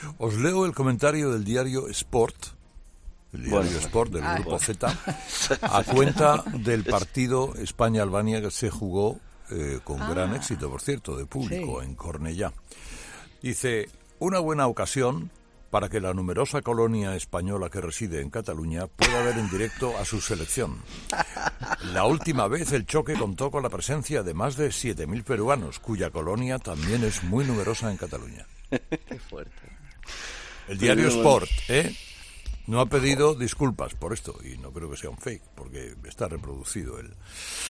"El diario Sport no ha pedido disculpas por esto y no creo que sea un fake", señaba el comunicador con sorpresa ante las risas y los comentarios de fondo de algunos de los tertulianos presentes en el programa que tacharon de "fuerte" la afirmación.